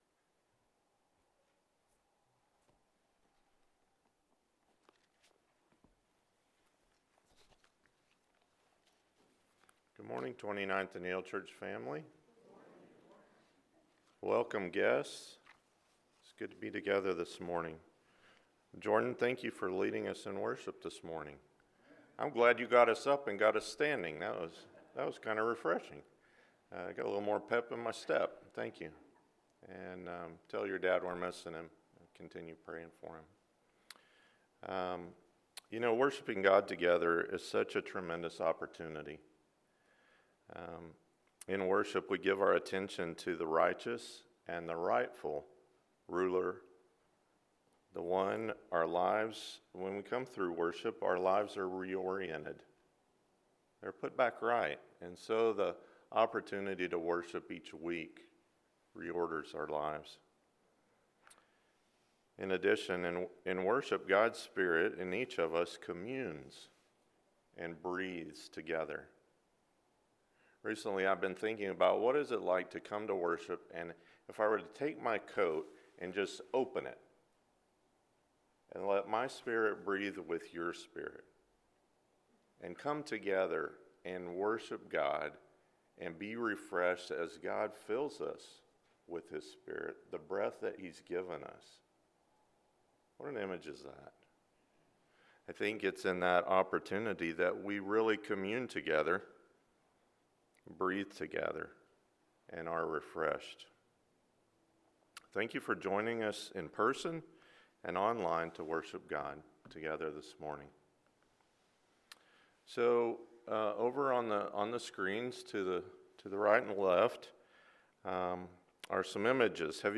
Letting Our Light Shine – Matthew 5:14-16 – Sermon